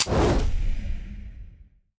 select-flame-2.ogg